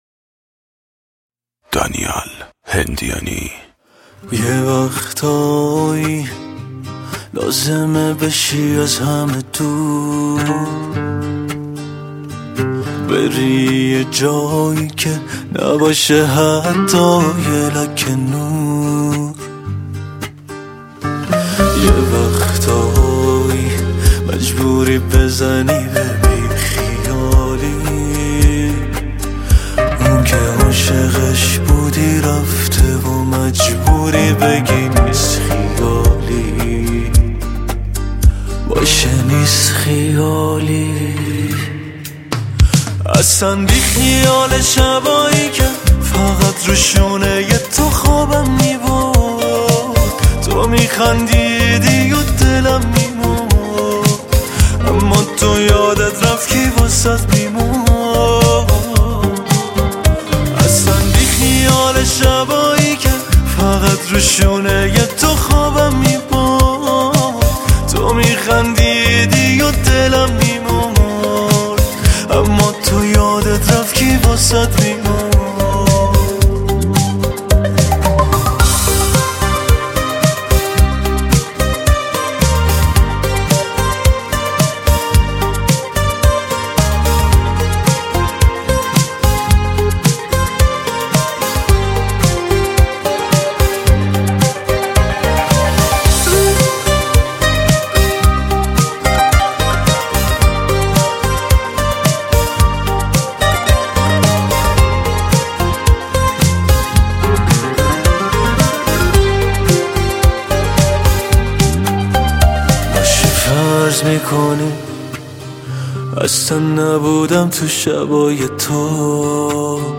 آهنگ زیبا ، شاد وشنیدنی